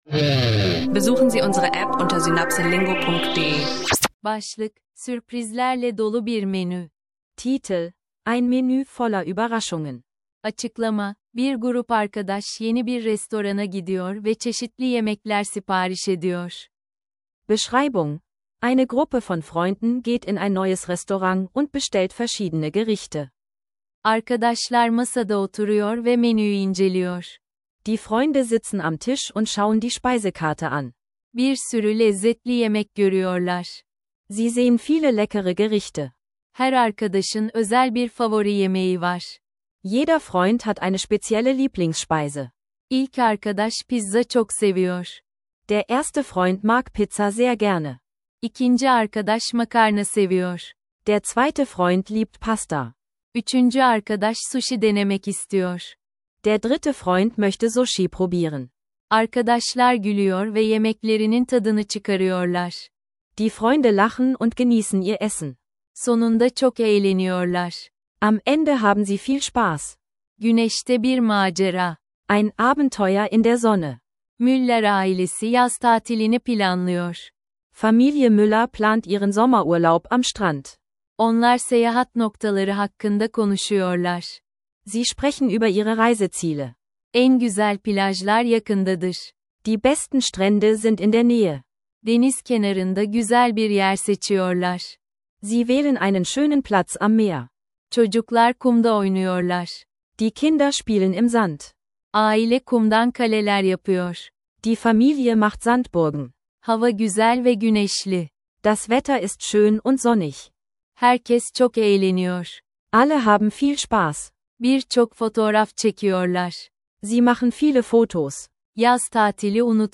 Lerne Türkisch mit interaktiven Gesprächen und neuen Vokabeln für den Alltag – Ideal für Anfänger und Reisende!